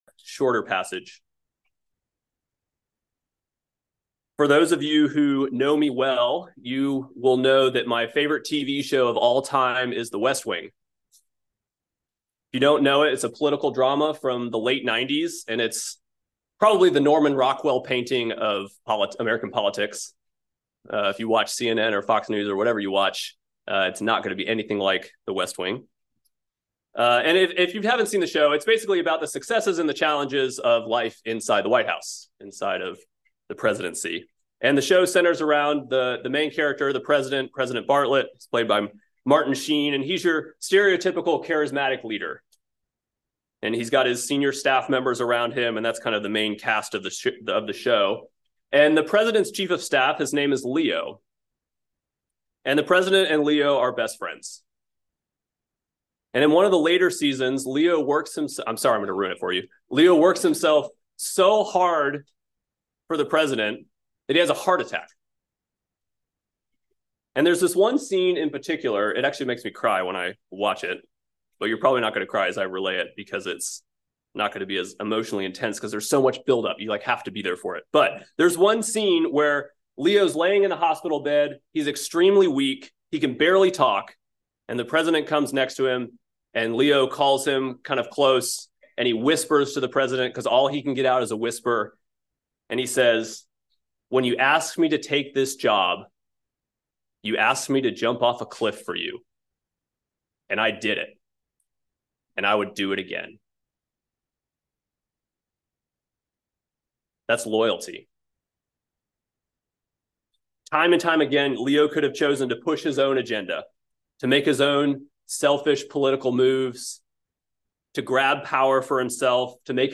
by Trinity Presbyterian Church | Jan 10, 2024 | Sermon